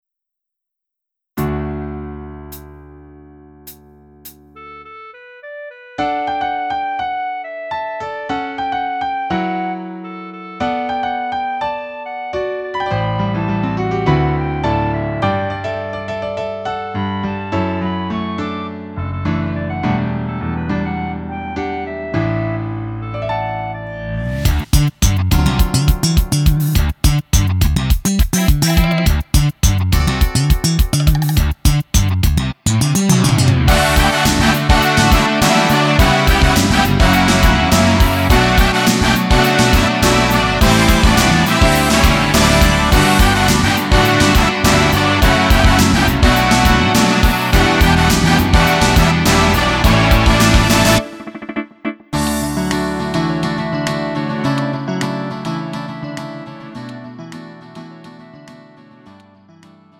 음정 원키 3:16
장르 가요 구분